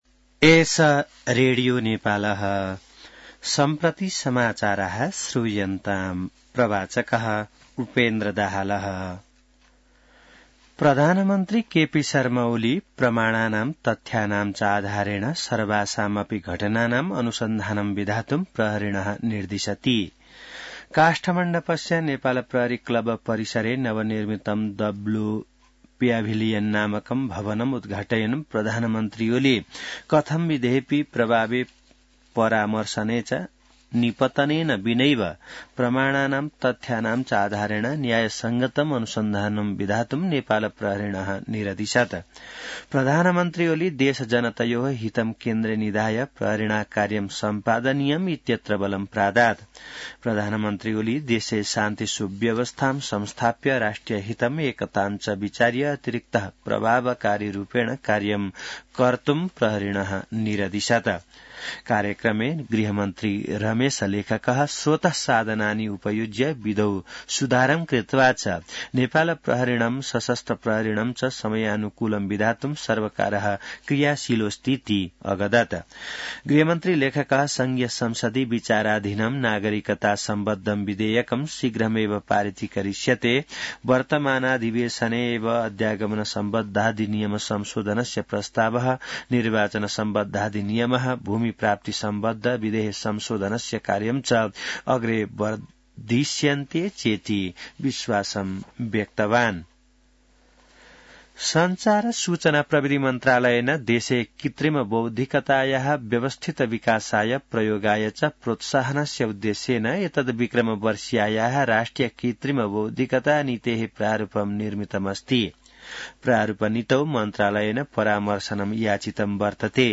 संस्कृत समाचार : २८ माघ , २०८१